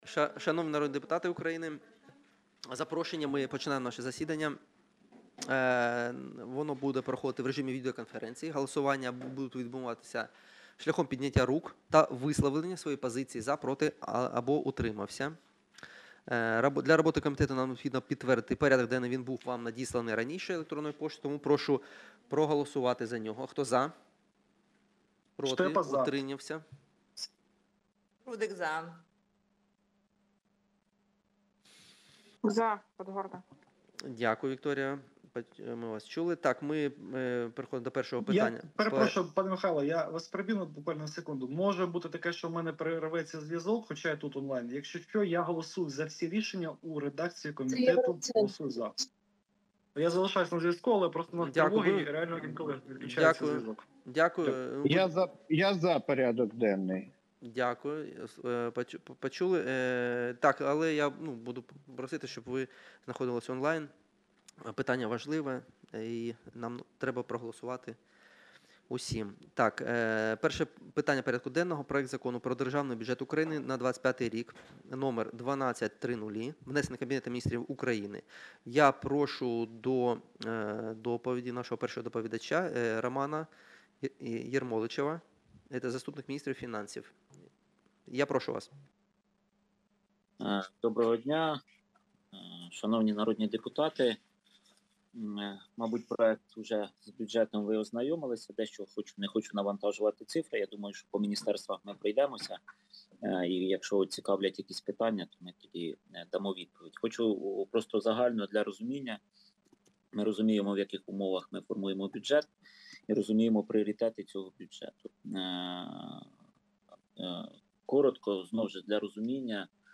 Аудіозапис засідання Комітету від 26.09.2024